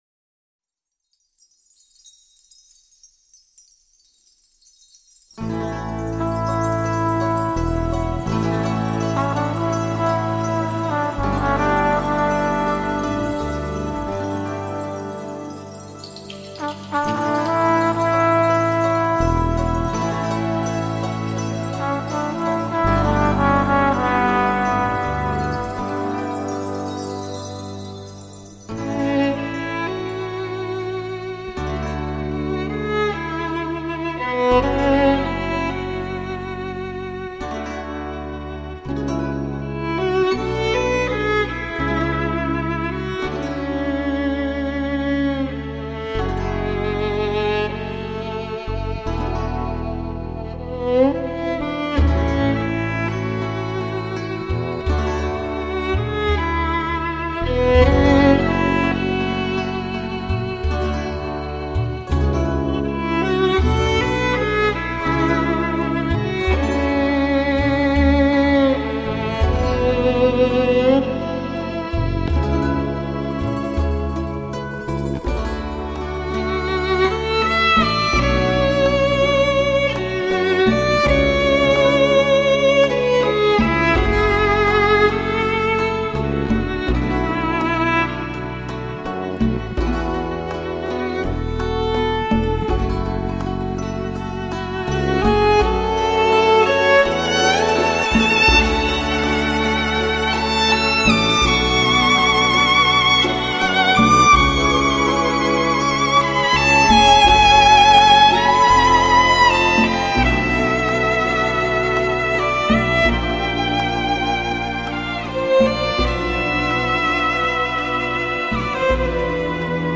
古典跨界小提琴缪斯女神